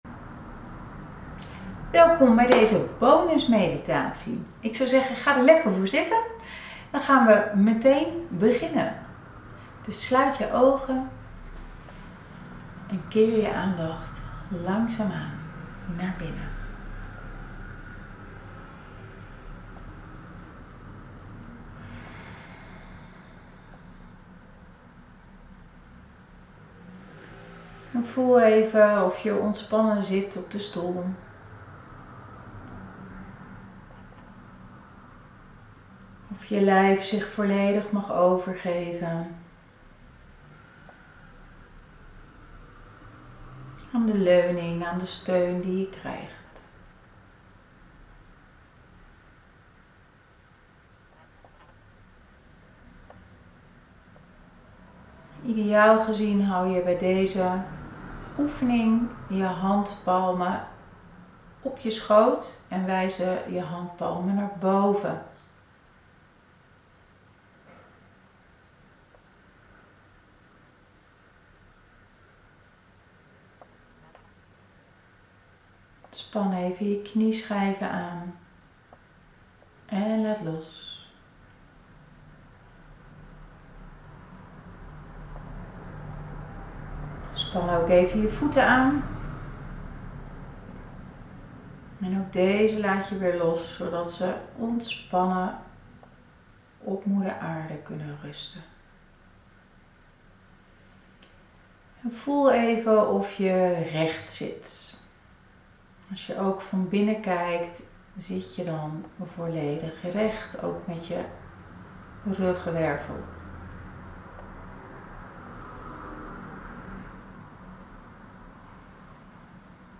9.1 Meditatie: Innerlijk Zijn